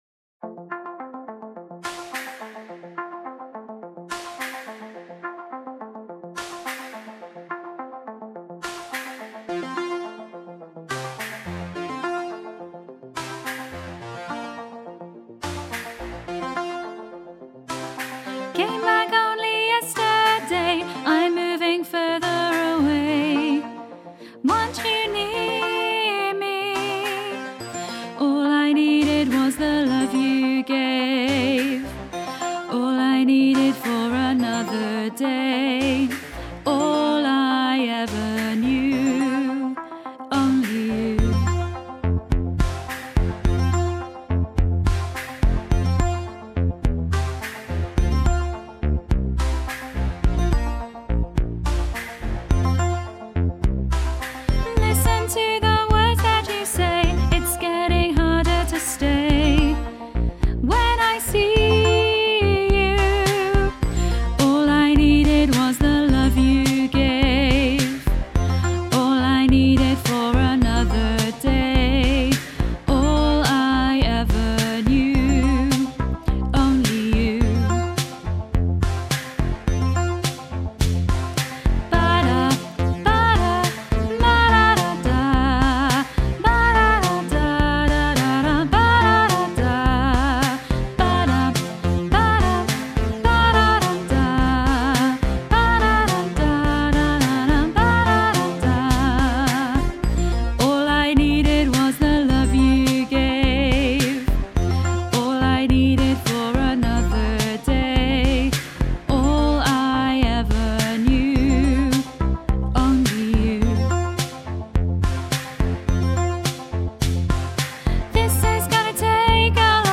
2-lower-part-women-only-you.mp3